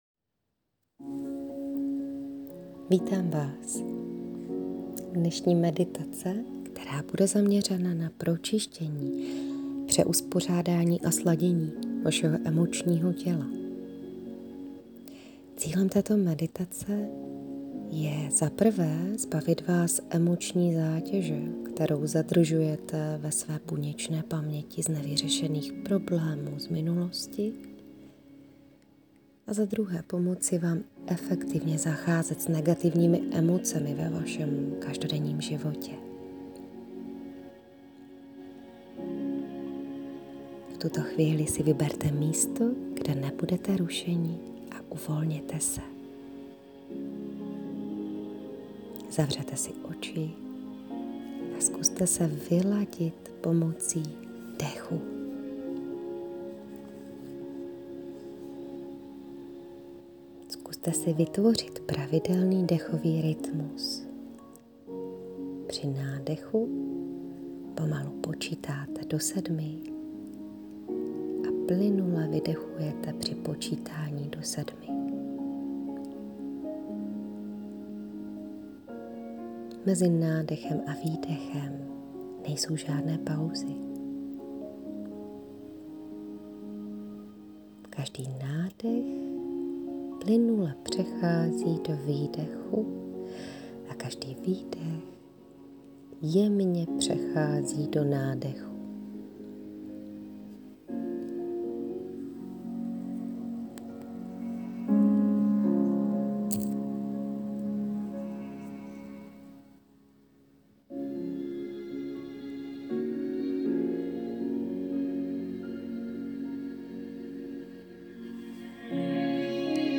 Meditace-na-procisteni-emocniho-tela.mp3